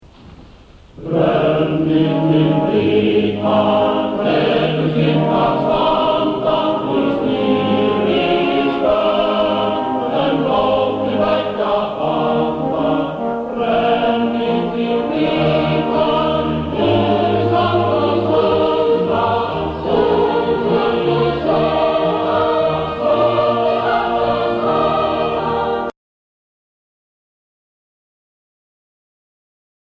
Flytjendur í tóndæminu: Söngsveitin Fílharmónía
og Sinfóníuhljómsveit Íslands undir stjórn Páls P Pálssonar
Hljómsveitarútsetning: Hallgrímur Helgason.
Kór.